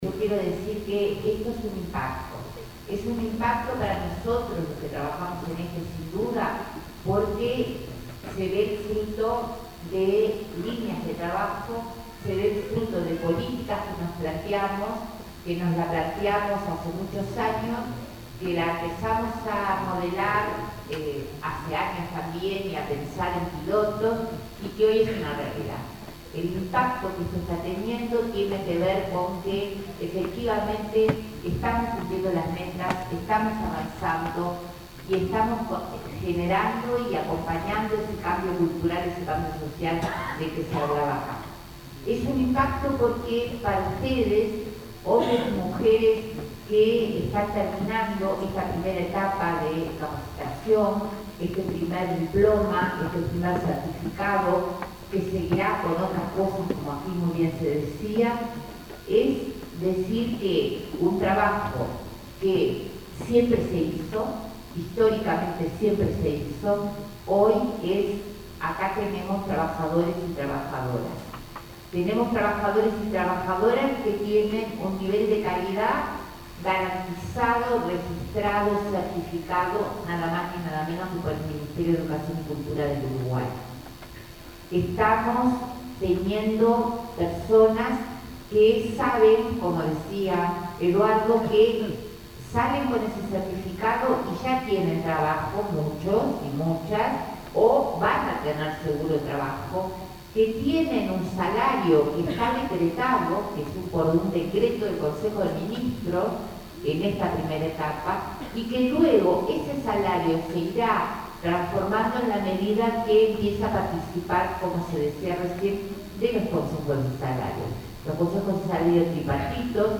La ministra de Desarrollo Social, Marina Arismendi, sostuvo que se están cumpliendo las metas dentro del Sistema Nacional de Cuidados. Al participar de la entrega de certificados de capacitación en atención a la dependencia, subrayó que esta nueva categoría de trabajadores certificados y calificados participarán de los Consejos de Salarios.